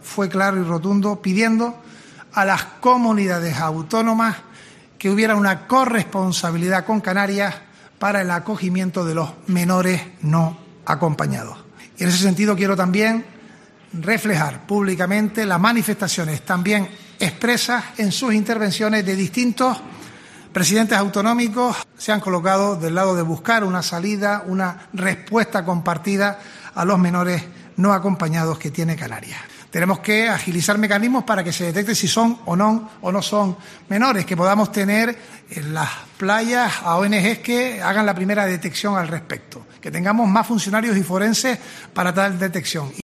Así lo ha trasladado en la rueda de prensa posterior a la XXVI Conferencia de Presidentes, que se ha celebrado en Los Llanos de Aridane y en la que tras unos diez años han vuelto a coincidir los presidentes de las 17 autonomías junto a Ceuta y Melilla.